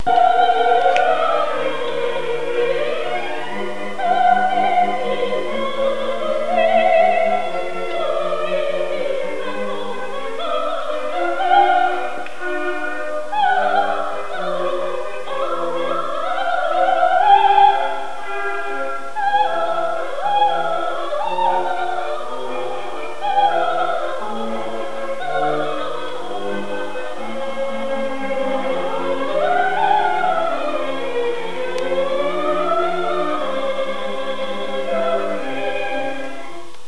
Mozart’s Mass in C Minor, South West Essex Choir